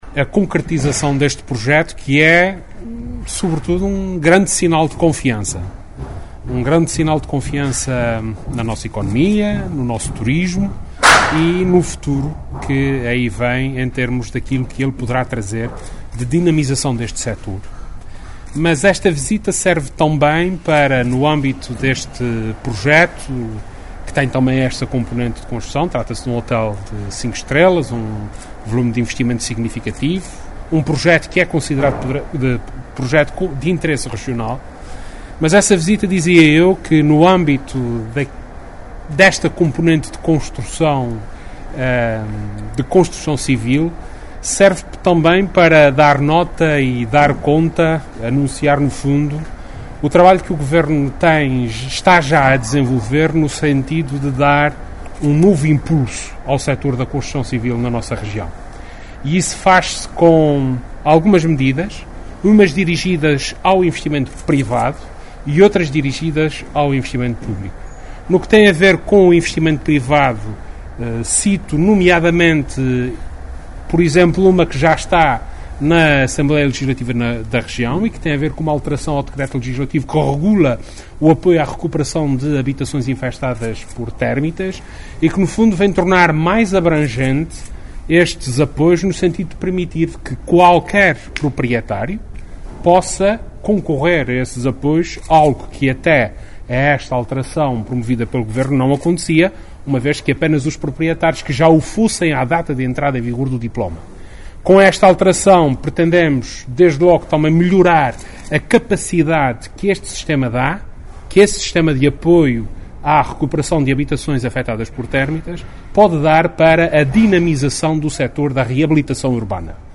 Vasco Cordeiro, que falava no final de uma visita às obras de construção de uma unidade hoteleira de cinco estrelas na costa norte da ilha de S. Miguel, frisou que o Governo dos Açores está a tomar medidas para promover a dinamização do setor da construção civil, não apenas ao nível do apoio ao investimento privado, mas também no que se refere ao investimento público.